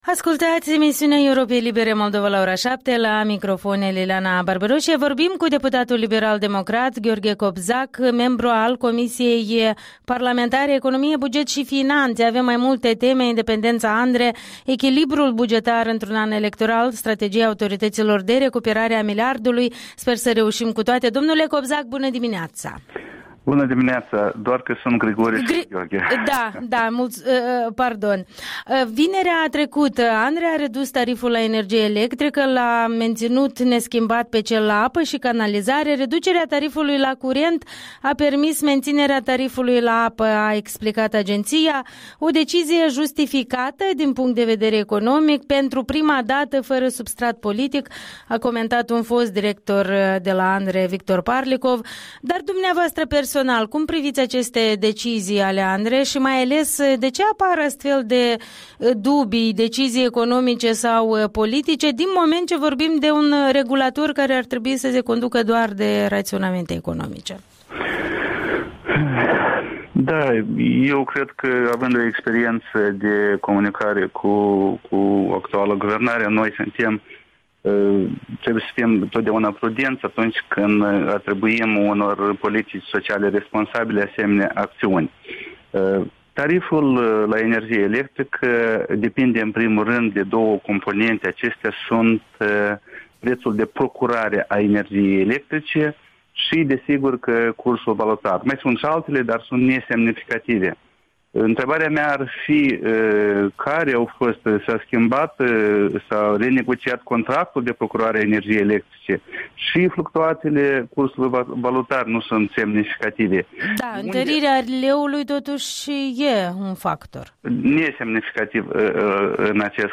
Interviul dimineții cu un membru al Comisiei parlamentare economie, buget şi finanţe, despre politica tarifară a ANRE.
Interviul dimineții: cu Grigore Cobzac